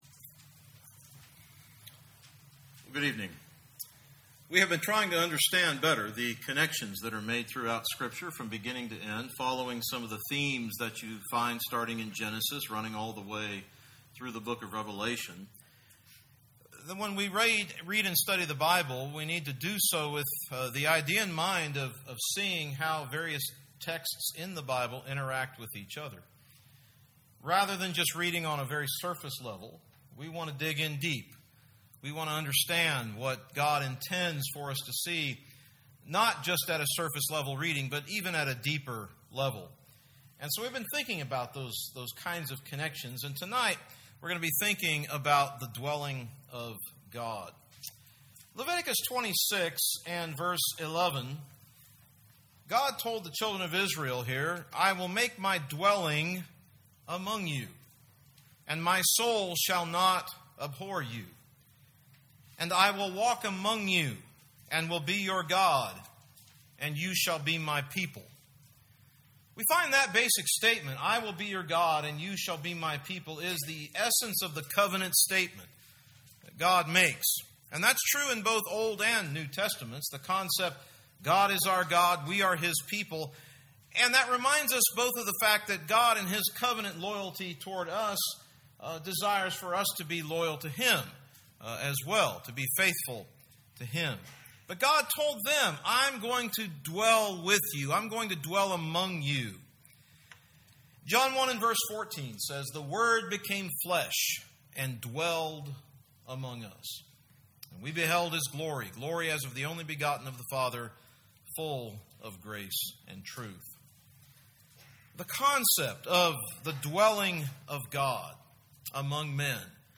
Gospel Meetings Service Type: Gospel Meeting Preacher